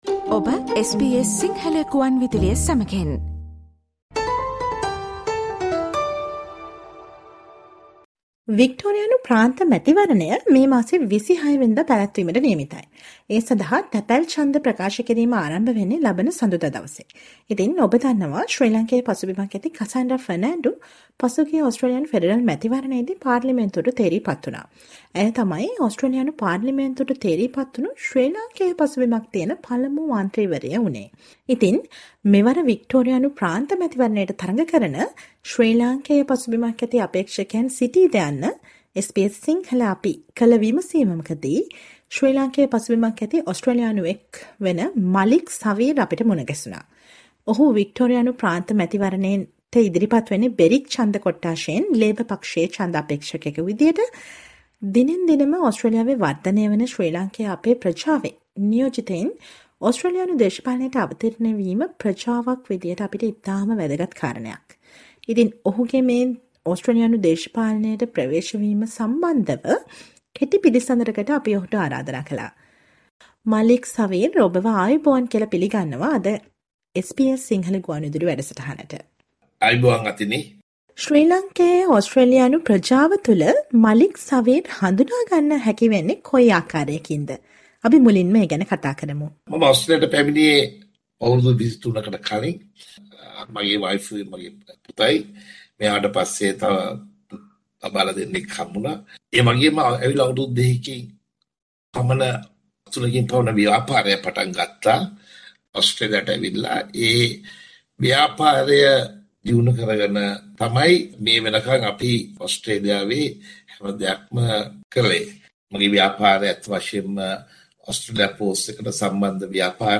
SBS Sinhala radio interview